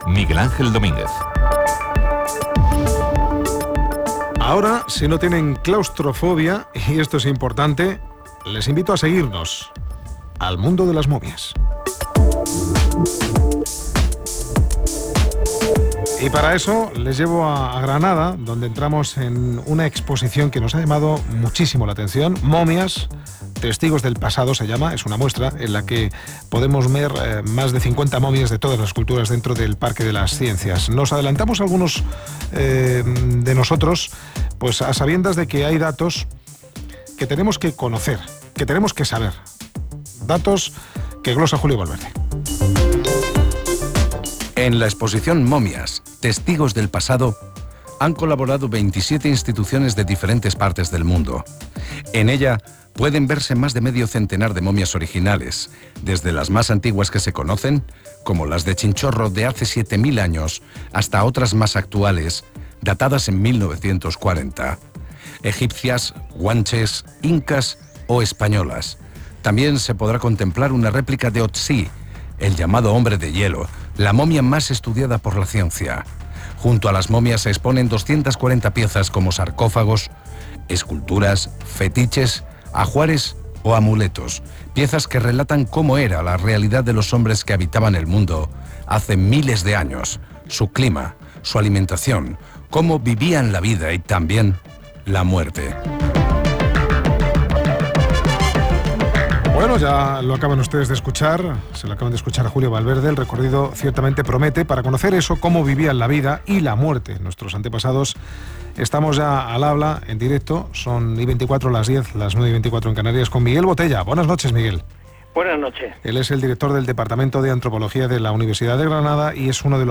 AUDIO: RNE 24 HORAS (TERTULIA): EXPOSICIÓN DE MOMIAS EN EL PARQUE DE LAS CIENCIAS DE GRANADA